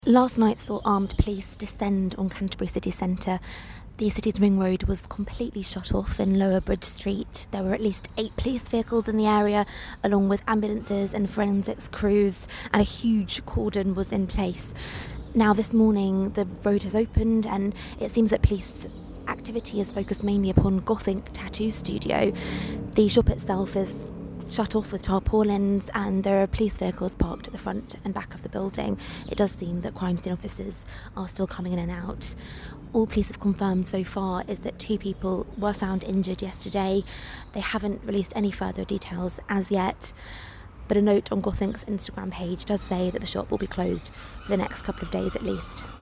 reporter
near GothInk in Canterbury